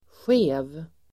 Uttal: [sje:v]